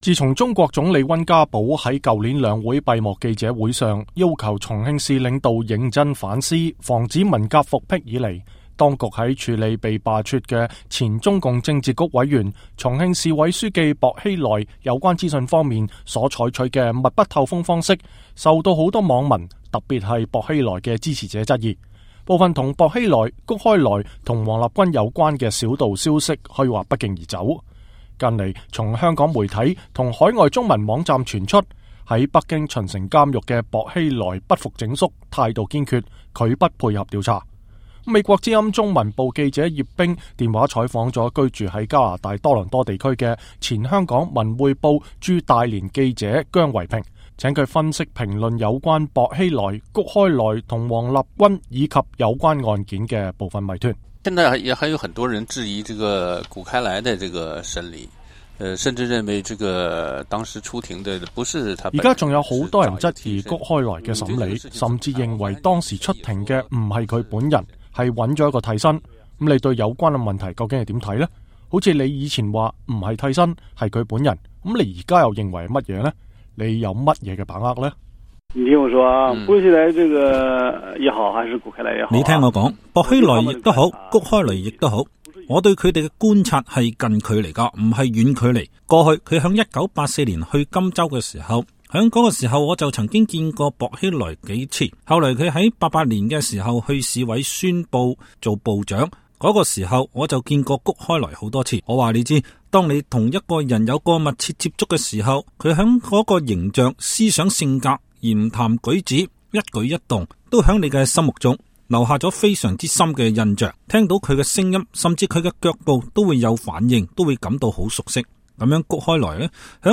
專訪